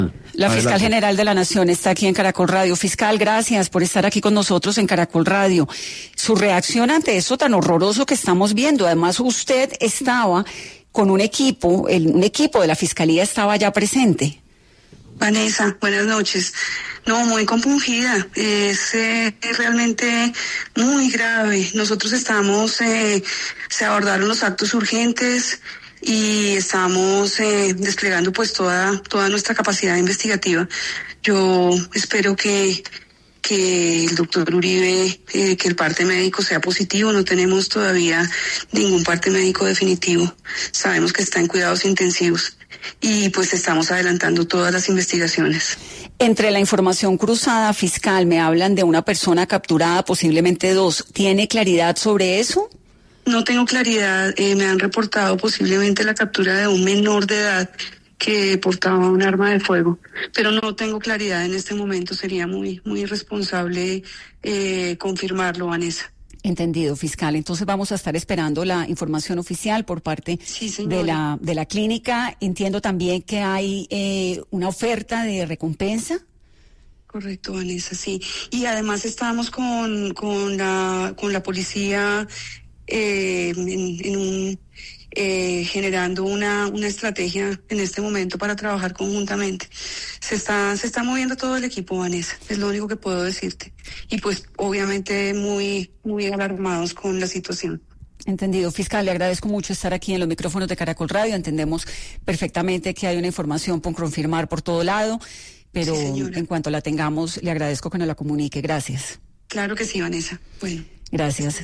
“Es realmente muy grave”, expresó la jefa del ente acusador en dialogo con Caracol Radio.